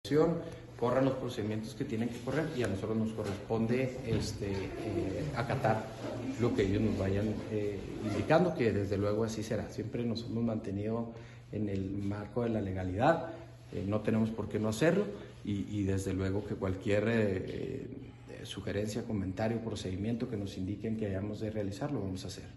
AUDIO: GILBERTO BAEZA MENDOZA, TITULAR DE LA SECRETARÍA DE SALUD ESTATAL